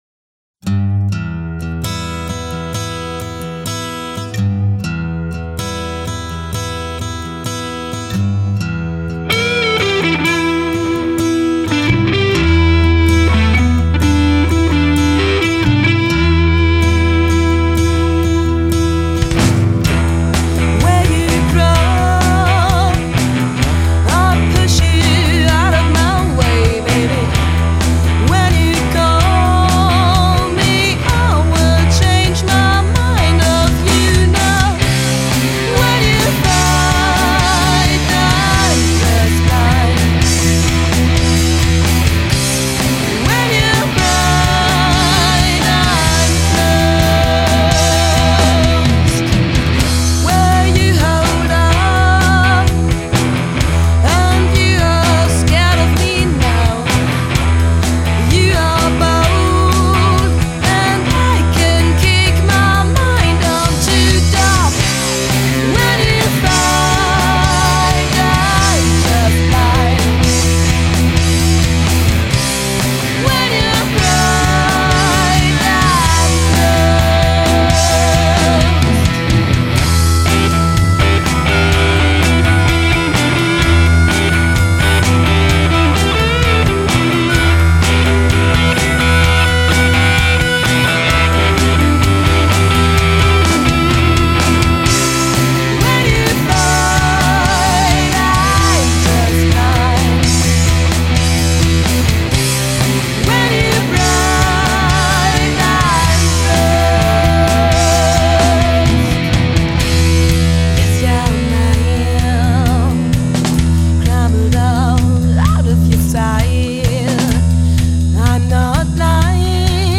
Drums
Lead Vocals
Backing Vocals
Guitar
Bass